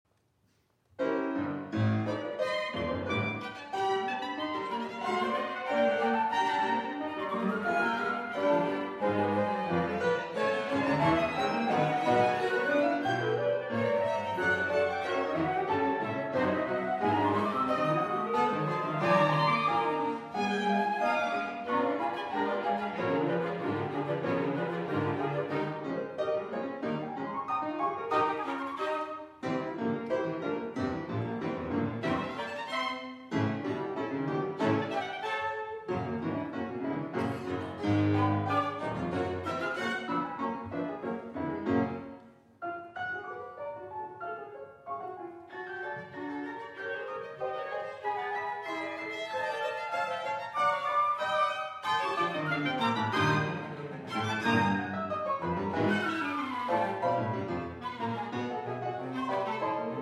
Violin
Flute
Cello
Clarinet